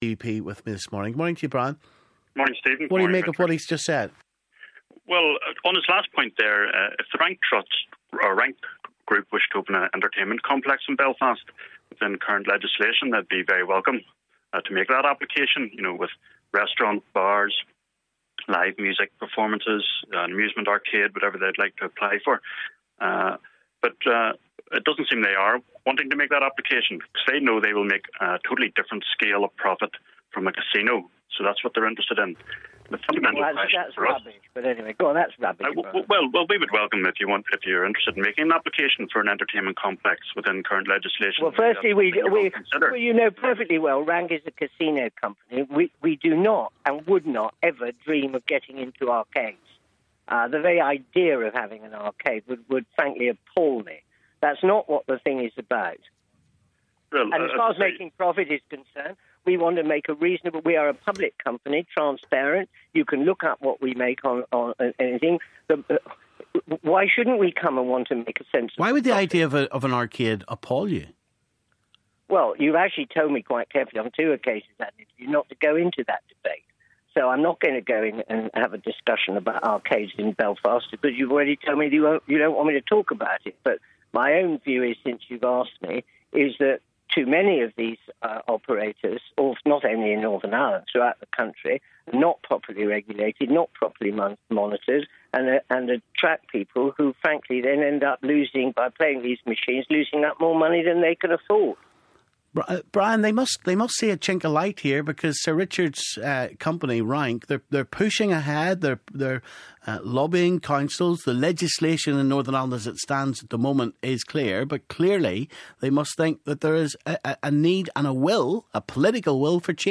The Belfast casino battle - Cllr Brian Kingston and Sir Richard Needham clash